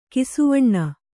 ♪ kisuvaṇṇa